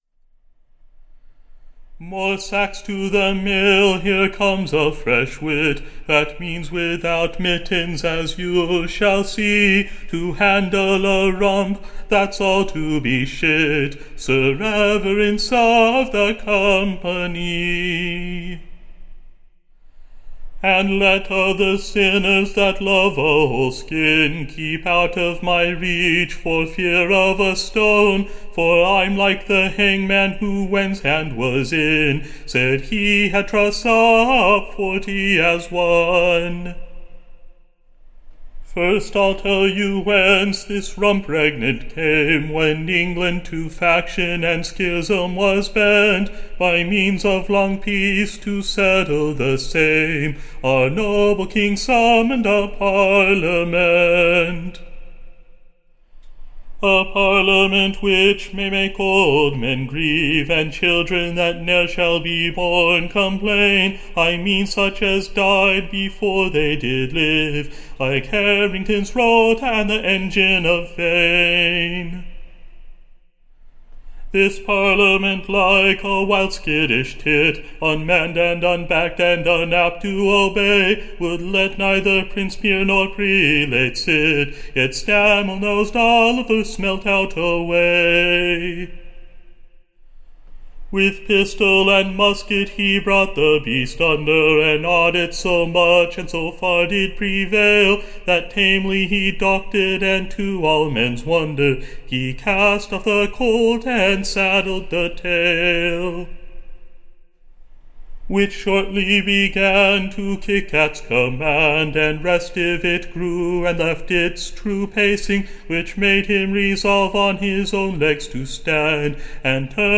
Recording Information Ballad Title THE / RUMP / Roughly but righteously handled, / In a New BALLAD: Tune Imprint To the tune of Cook Lorrel.